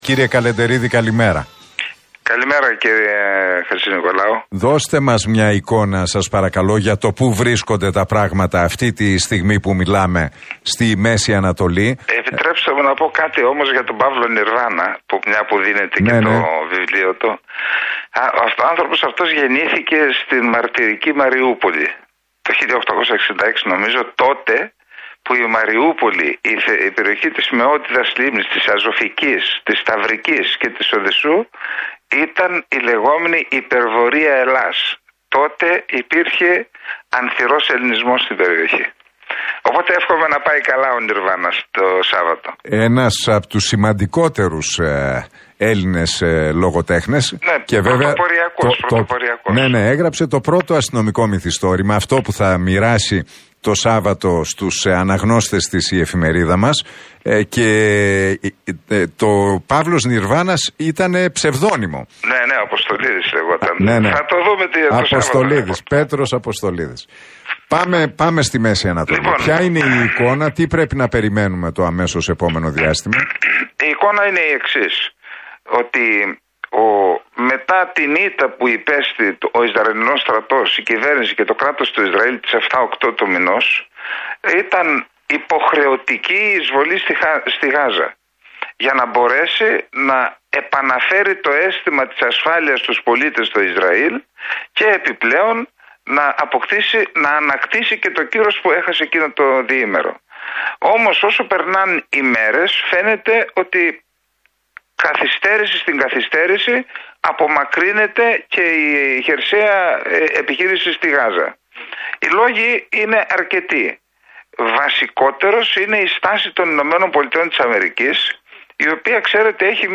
«Καθυστέρηση, στην καθυστέρηση φαίνεται να απομακρύνεται η χερσαία επιχείρηση στην Γάζα. Οι λόγοι είναι πολλοί. Βασικότερος είναι η στάση των ΗΠΑ, που έχουν μια ιδιαίτερη εμπλοκή: κατοικούν αυτή τη στιγμή στο Ισραήλ 600.000 Αμερικανοί πολίτες συν το ότι υπάρχουν και όμηροι Αμερικανοί στα χέρια της Χαμάς» δήλωσε ο Σάββας Καλεντερίδης μιλώντας στον Νίκο Χατζηνικολάου και στον Realfm 97,8.